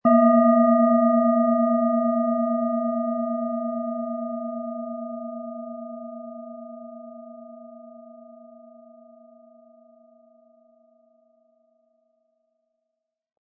Planetenton 1
Sanftes Anspielen mit dem gratis Klöppel zaubert aus Ihrer Schale berührende Klänge.
SchalenformBihar
MaterialBronze